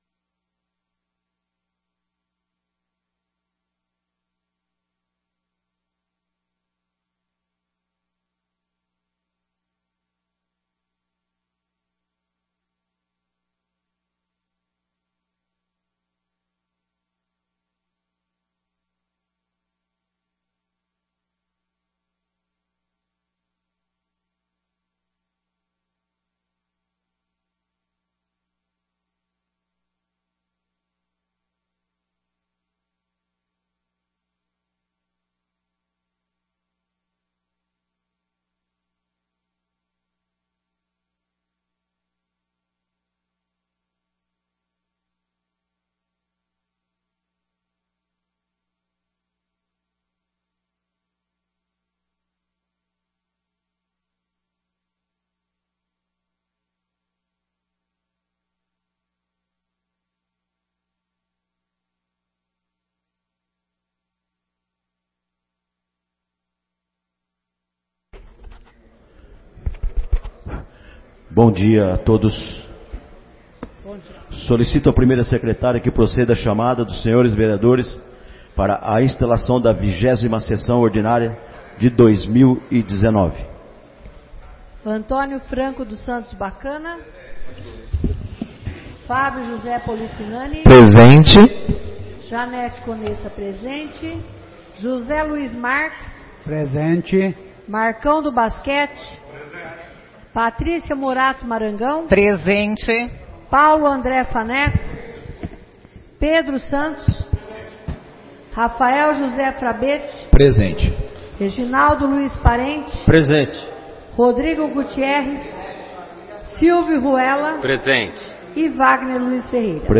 20ª Sessão Ordinária de 2019